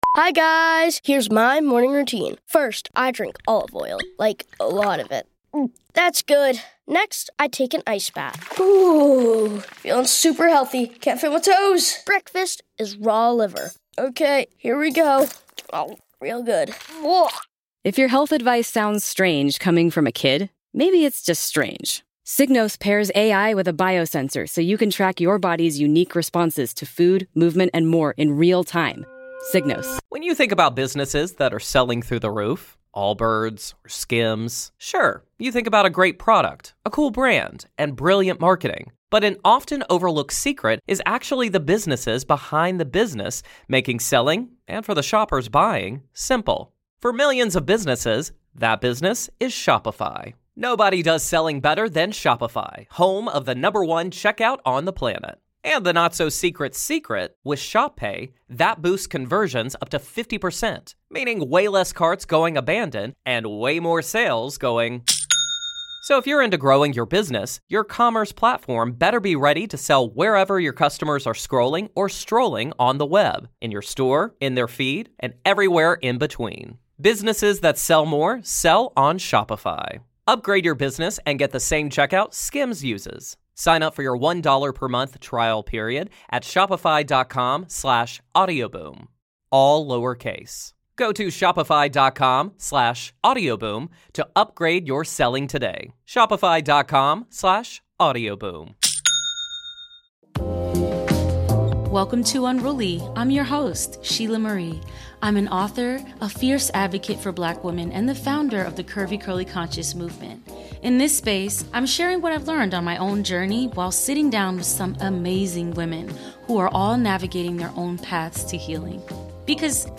for a deeply honest conversation about healing, homecoming, and why Detty December in Ghana is more than a trip—it’s a portal. Together, they unpack how ancestral travel can be a sacred act of reclamation, how Black women can unlearn the performance of survival, and what Detty December really means.